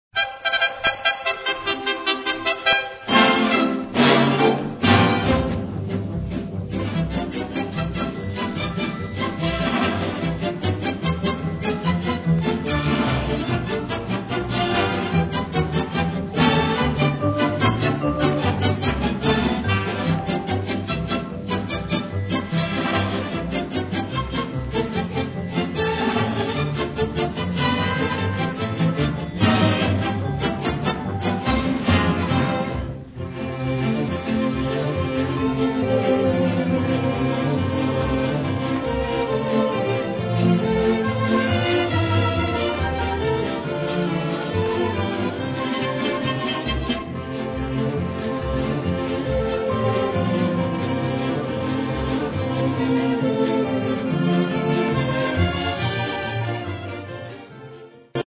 Signature Tune